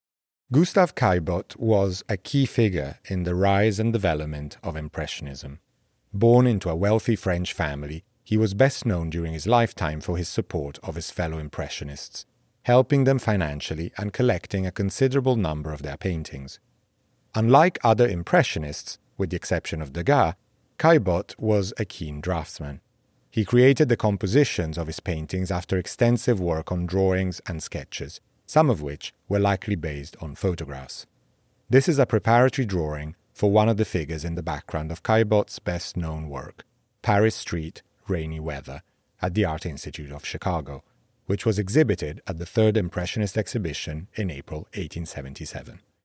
Each object is accompanied by an audio recording of the label text.